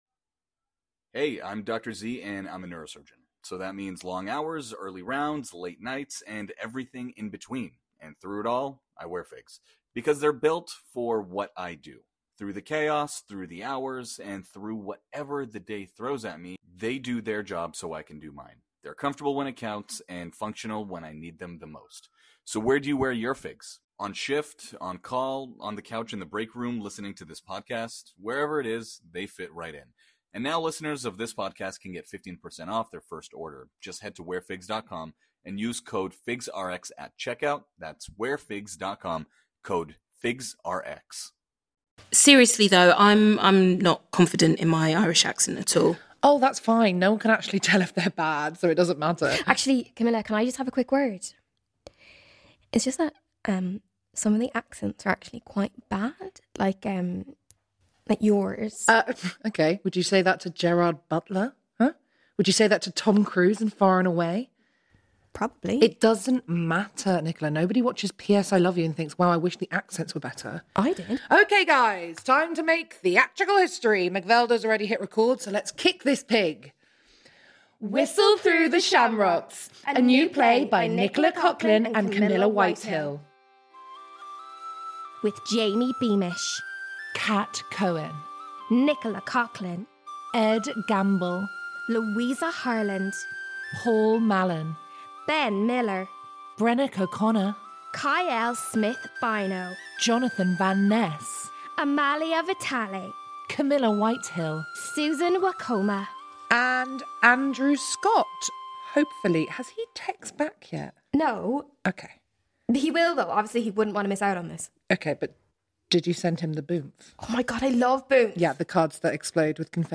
A comedy